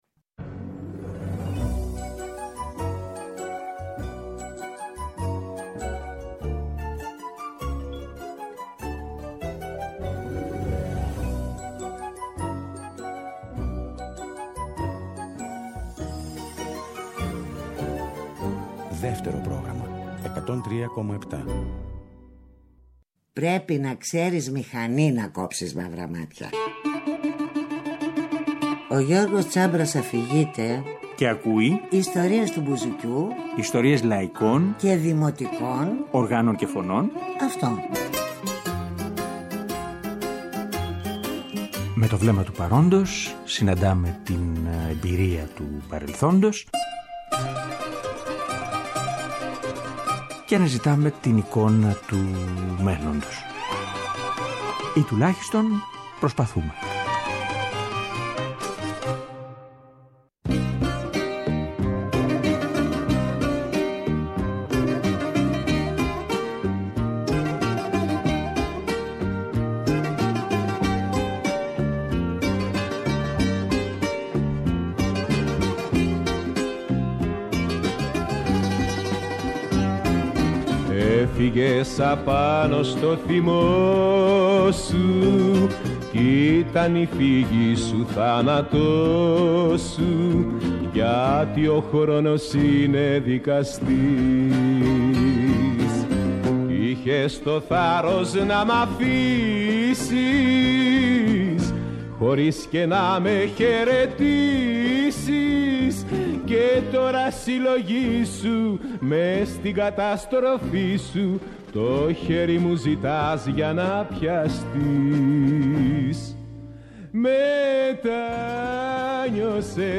Θα …ακούσουμε όμως τα τραγούδια!